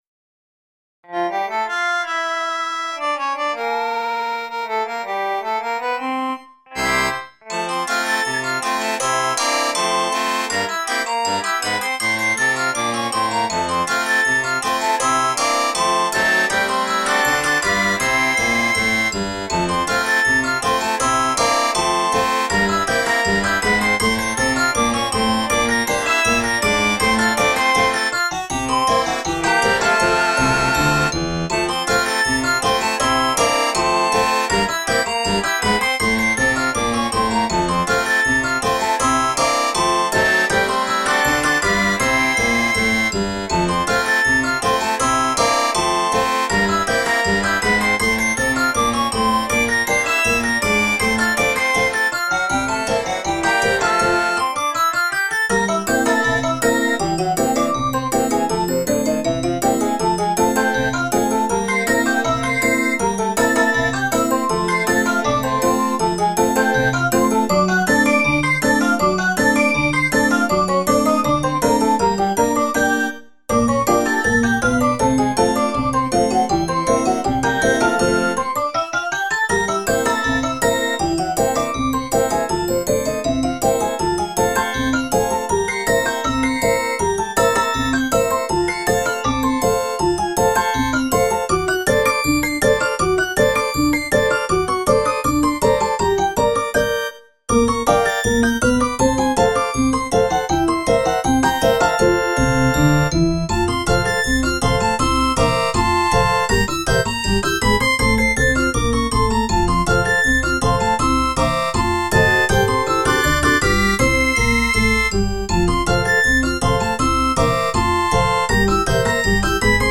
Demented 19th century children's music.
Circuses and carousels and calliopes, oh my!
Tagged as: Classical, Experimental, Experimental Electronic